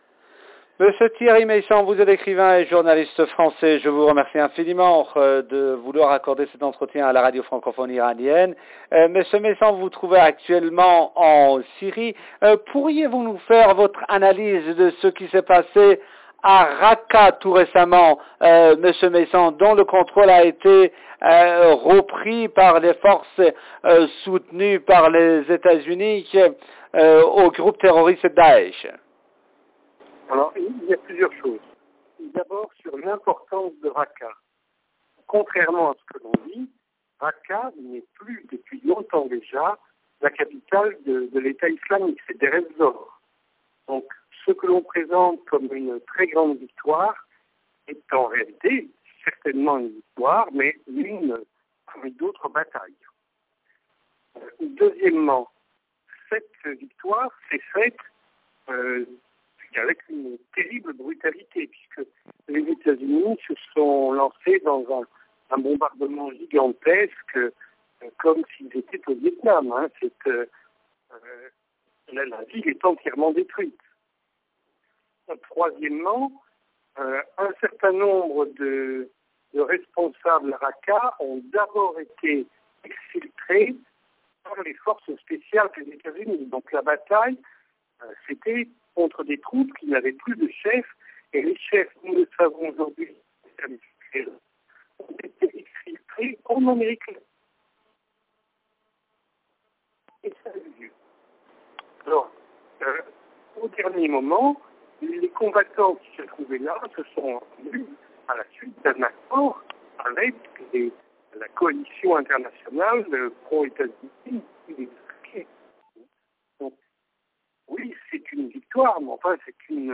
Thierry Meyssan a analysé dans une interview accordée à la Radio francophone iranienne les récents évènements survenus à Raqqa.